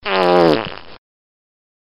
Pranks
Wet Fart Squish